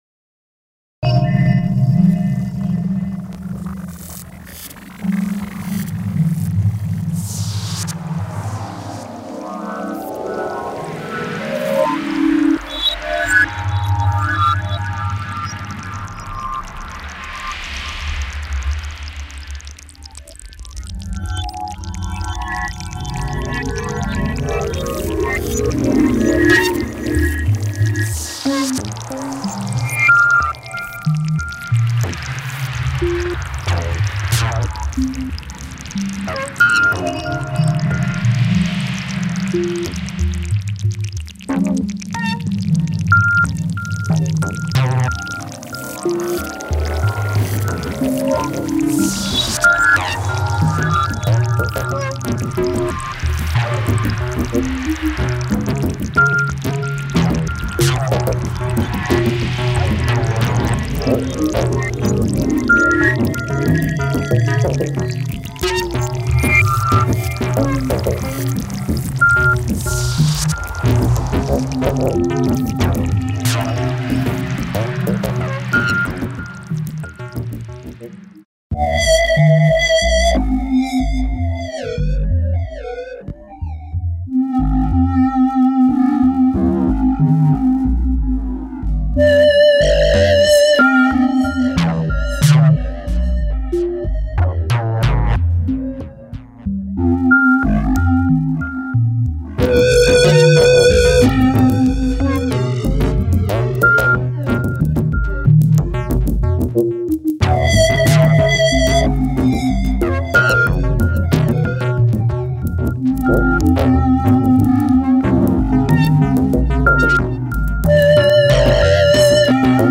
Grooved electronica.